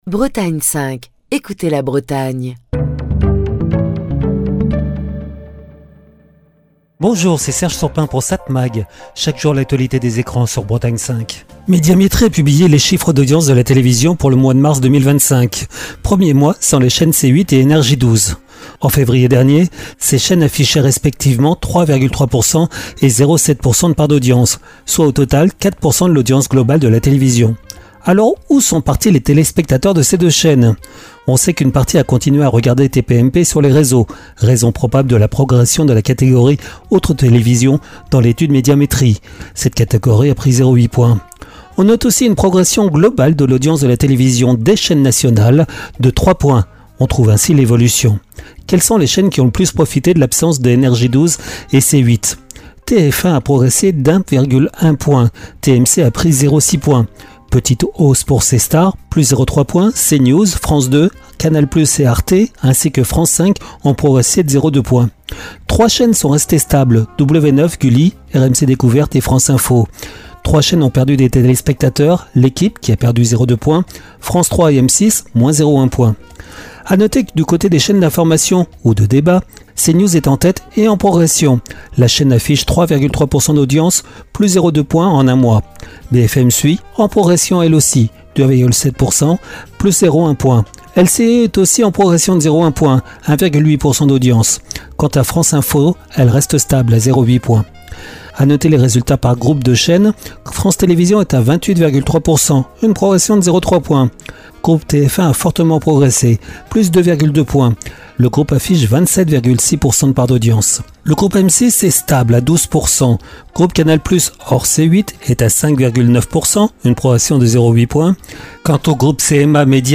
Chronique du 1er avril 2025. Médiamétrie a publié les chiffres d’audience de la télévision pour le mois de mars 2025, premier mois sans les chaînes C8 et NRJ 12.